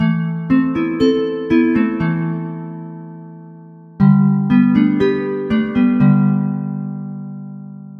标签： 120 bpm Chill Out Loops Harp Loops 2.29 MB wav Key : Unknown
声道立体声